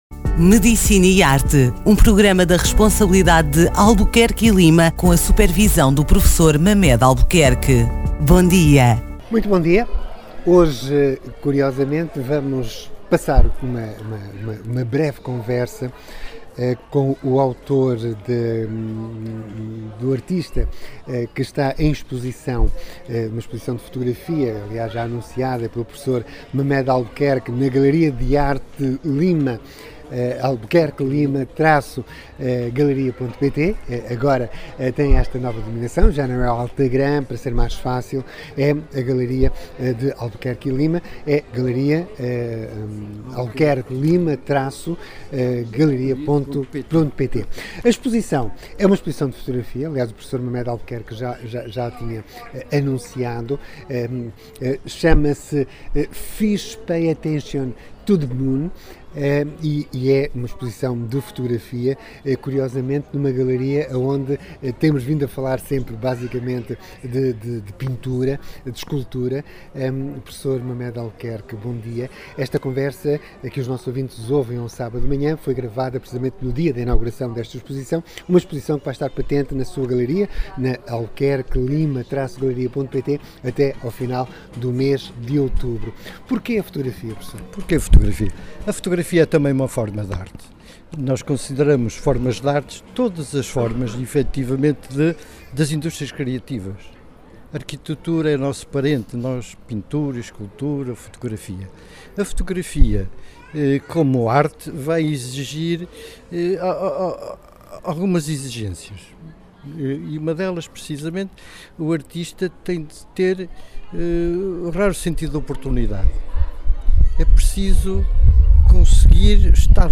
Uma conversa